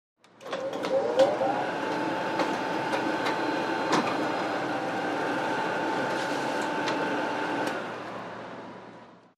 Xerox Machine Copying Document.